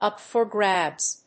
アクセントúp for grábs